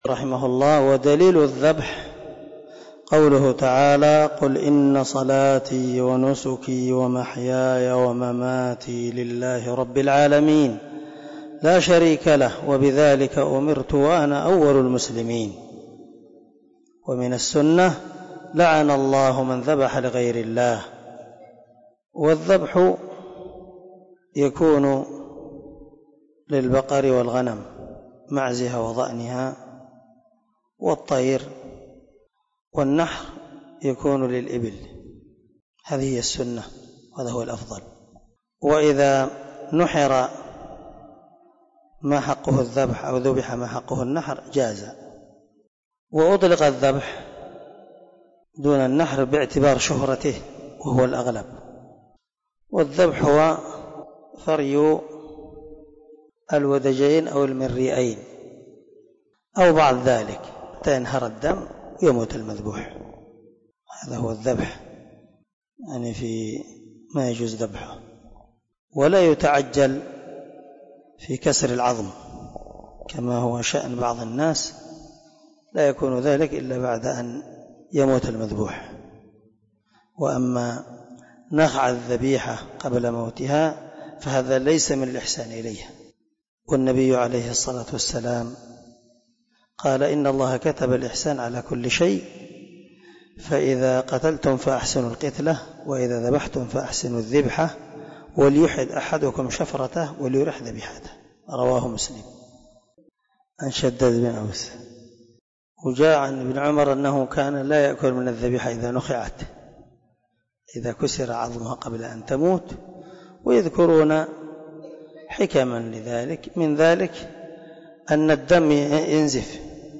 🔊 الدرس 20 من شرح الأصول الثلاثة